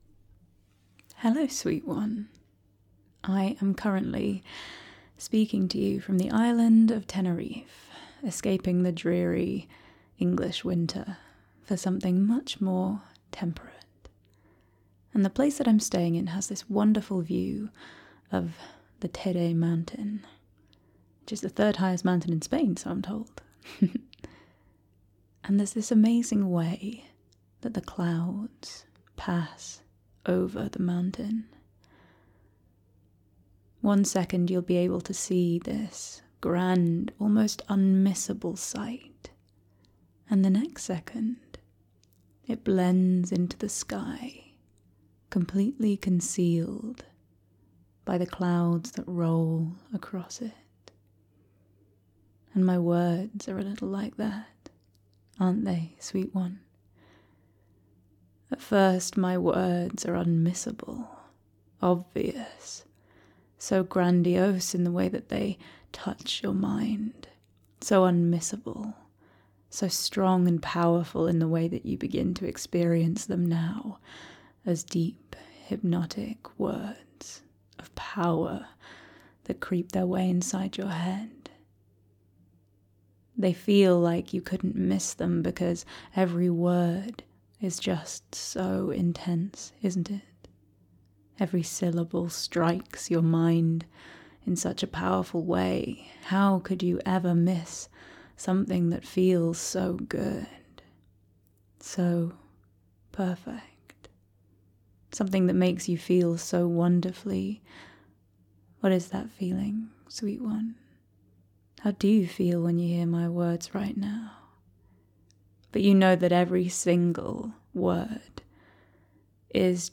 Everything about your voice is just so magnetic.